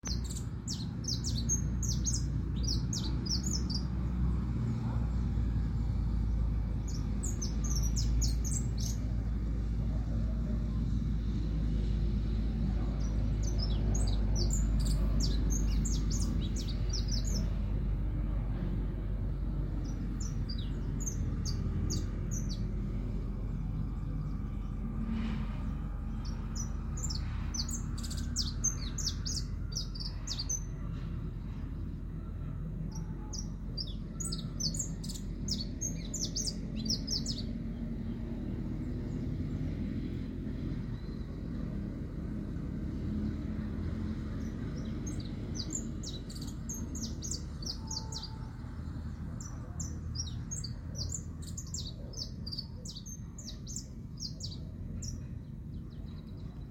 Sicalis flaveola pelzelni
English Name: Saffron Finch
Location or protected area: Santa María
Condition: Wild
Certainty: Recorded vocal
JILGUERO-DORADO.mp3